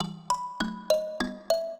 mbira
minuet9-3.wav